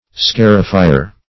Scarifier \Scar"i*fi`er\, n.